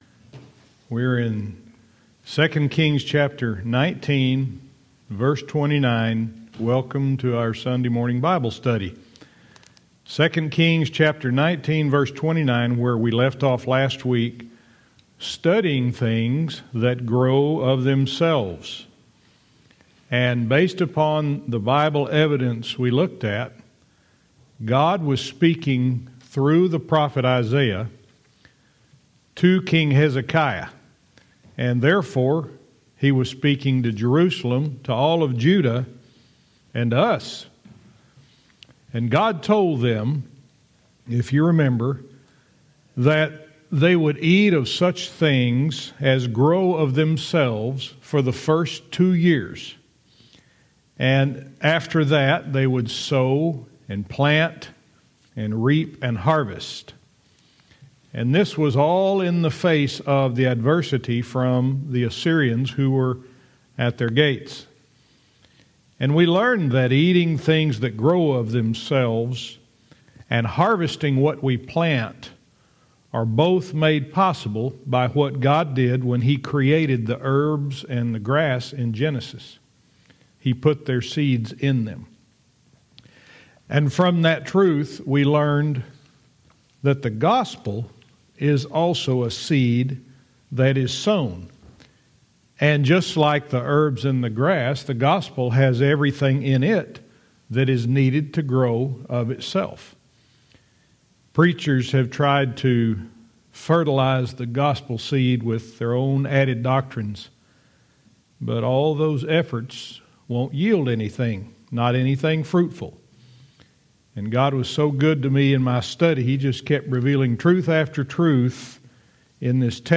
Verse by verse teaching - 2 Kings 19:29-31